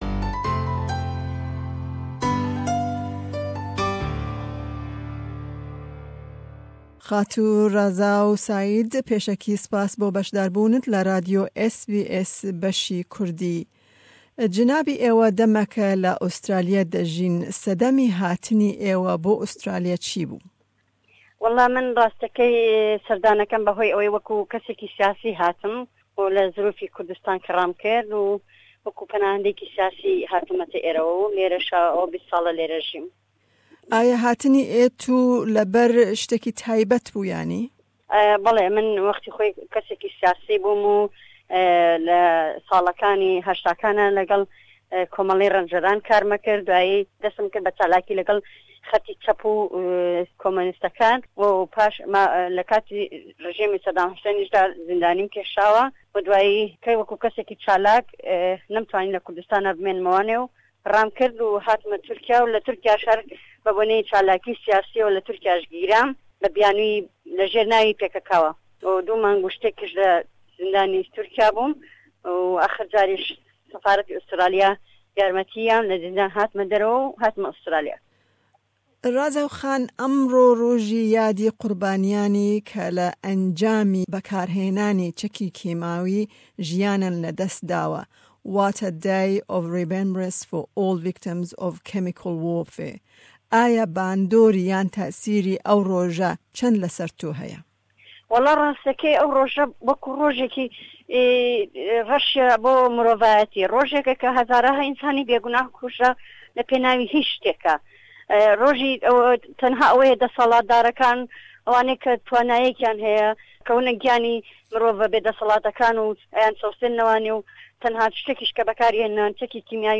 me derbarî vê rojê bi 2 kese ji civaka Kurd re axaft